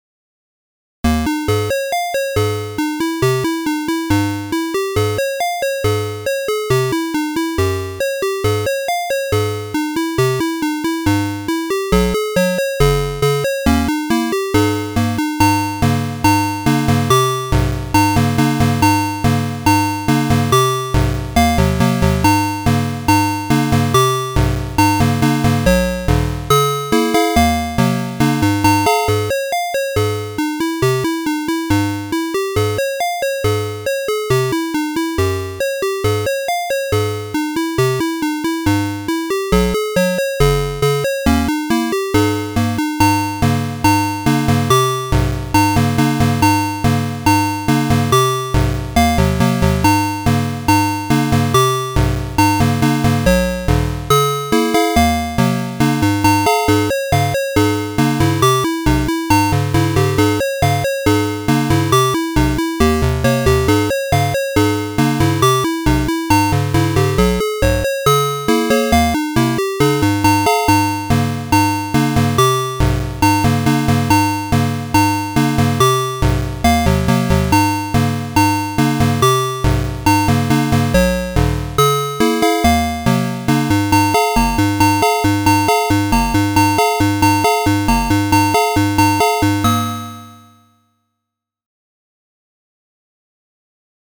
Atari-ST Emulation